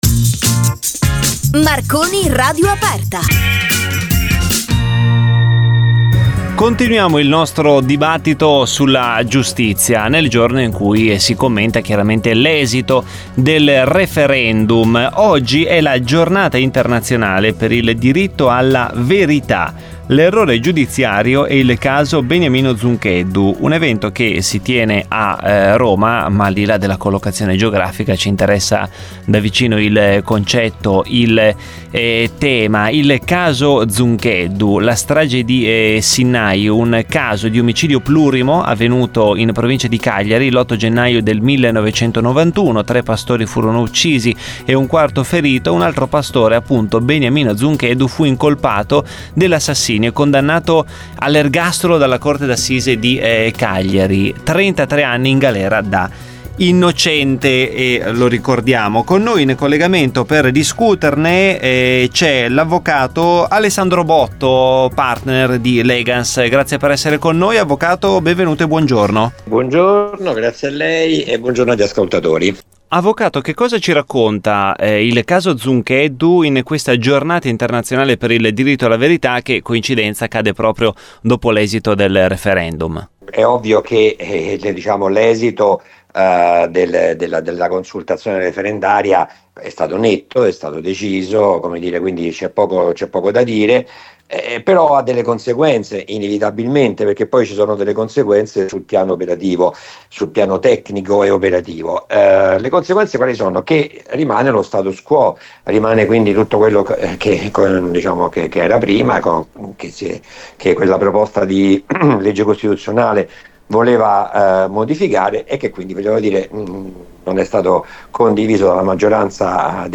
Intervista radiofonica